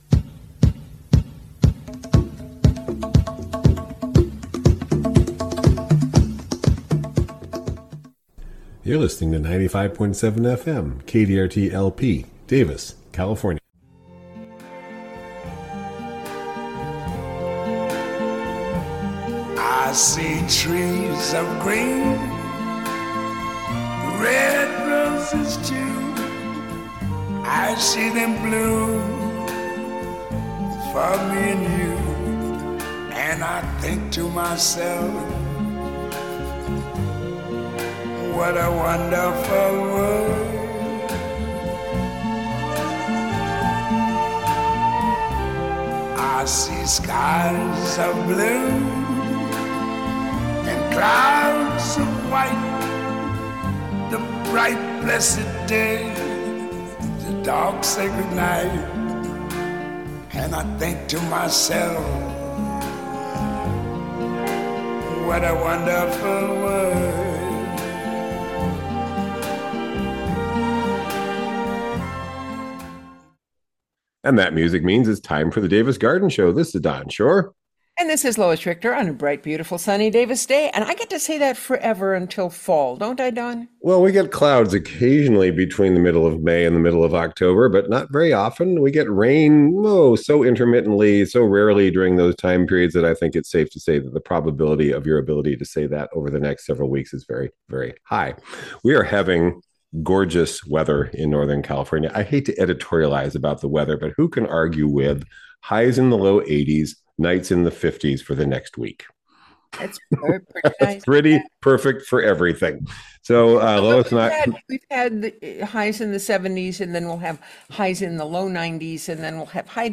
Spring gardening conversations